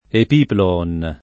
[ ep & plo-on ]